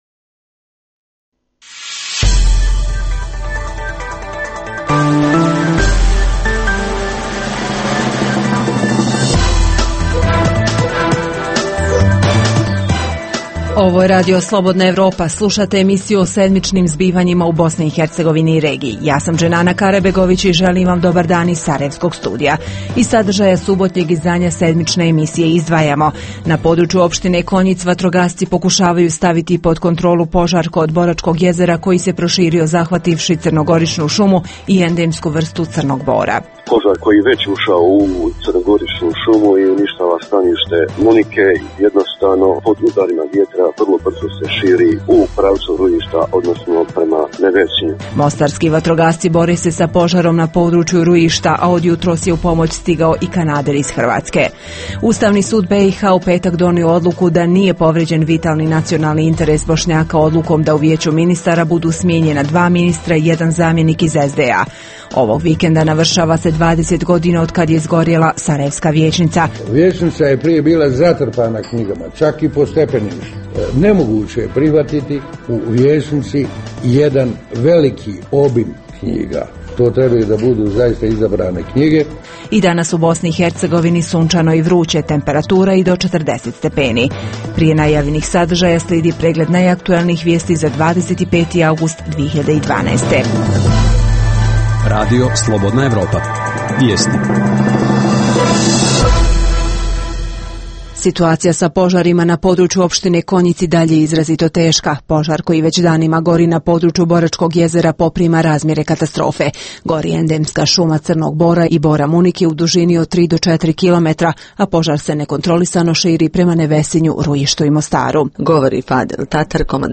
Emisija je namijenjena slušaocima u Bosni i Hercegovini. Sadrži lokalne, regionalne i vijesti iz svijeta, te tematske priloge o aktuelnim dešavanjima u Bosni i Hercegovini protekle sedmice.